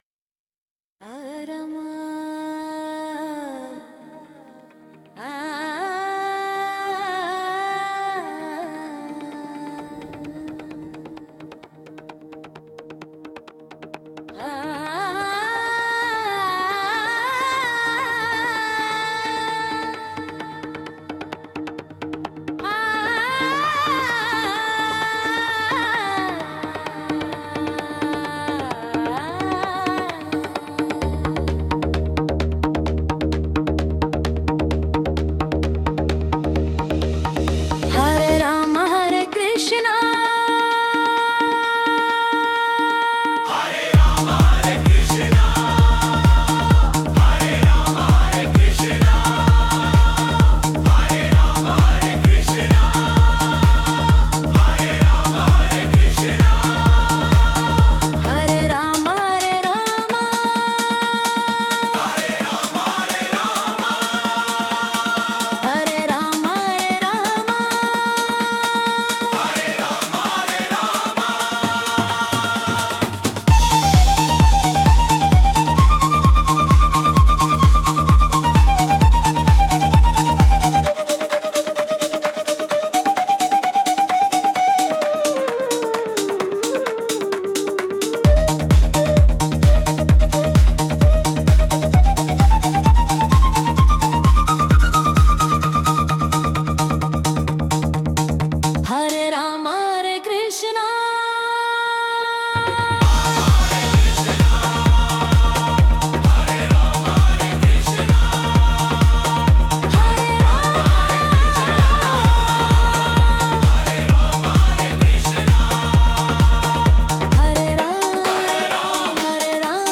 130-bpm инди-техно бомбу